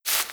SandStep4.wav